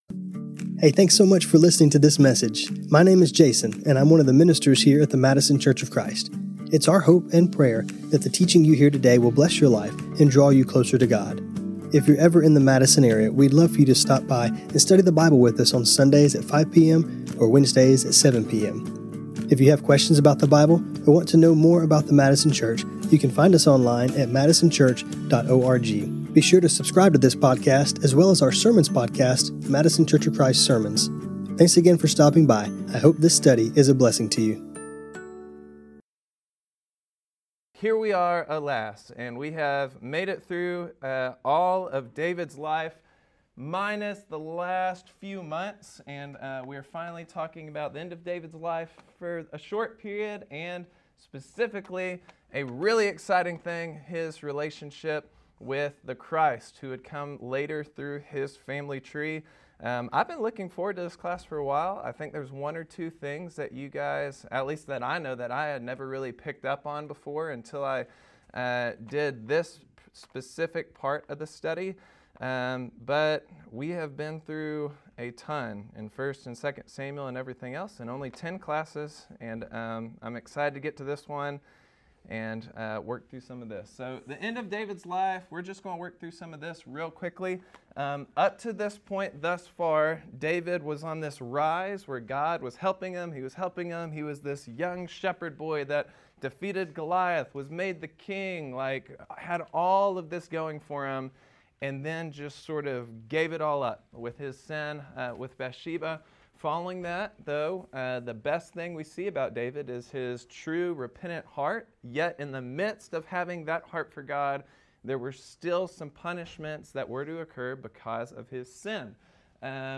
The video played towards the end of class came from The Bible Project and is titled Gospel of the Kingdom Check out the church archives including slides for this class Find us on Facebook.